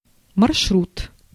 Ääntäminen
IPA: [ɛ̃ ʃə.mɛ̃]